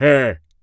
Male_Grunt_Hit_Neutral_06.wav